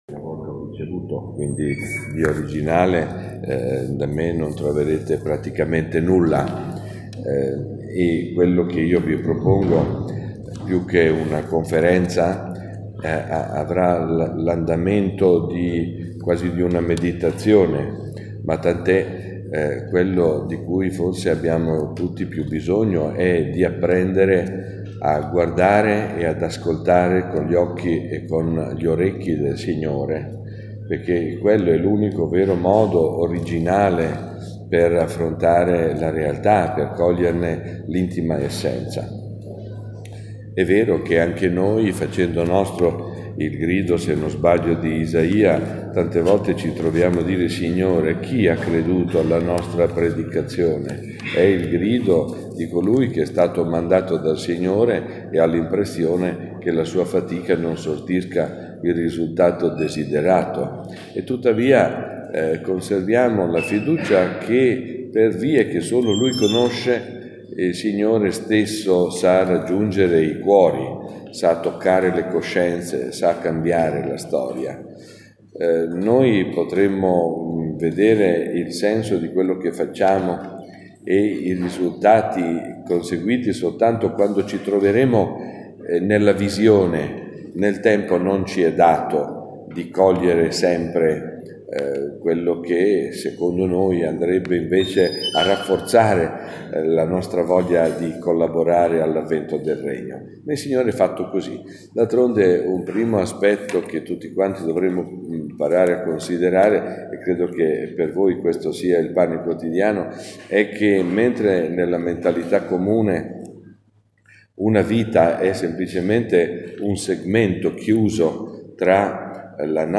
Incontro formativo dei Cappellani sanitari – 23 Ottobre 2019- Relazione Mons. Daniele Libanori | Ufficio Pastorale della Salute